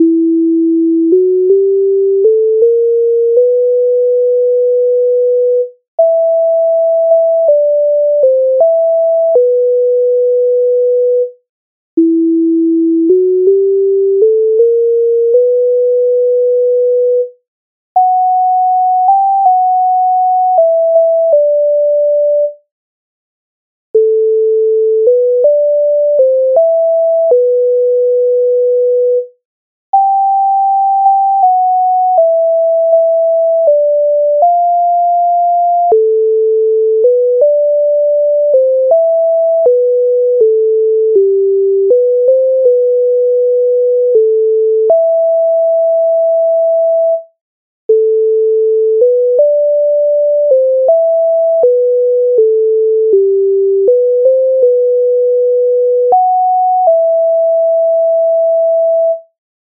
Гей ви козаченьки Українська народна пісня
Ukrainska_narodna_pisnia_Hej_vy_kozachenky.mp3